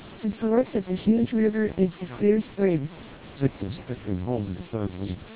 Below, you can listen to a short fragment of heavily noisy English speech after passing through MELPe and TWELP vocoders, with NPP (Noise Pre-Processor) and NCSE disabled and enabled, respectively.
(SNR=10dB)  MELPe
twelp480_ae_short_snr10db.wav